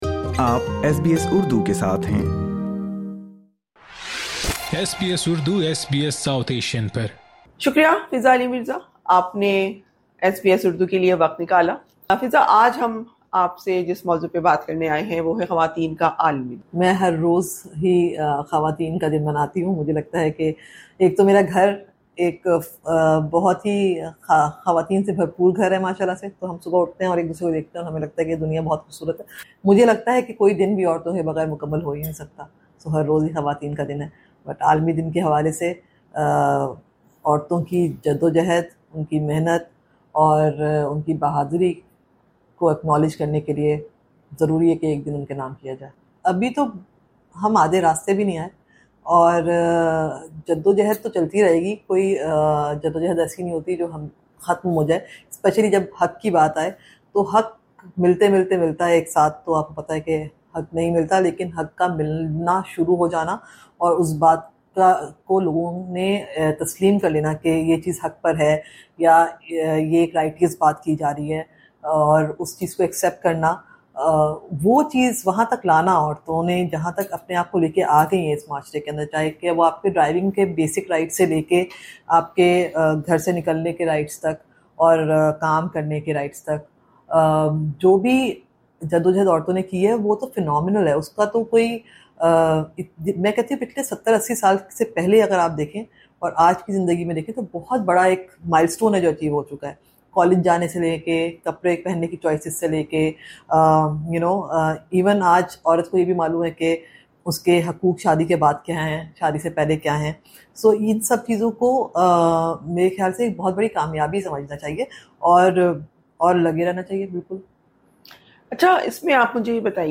On the occasion of International Women’s Day, in an exclusive conversation with SBS Urdu, she noted that while the number of women in Pakistan’s film industry remains low, awareness of women’s rights is steadily increasing.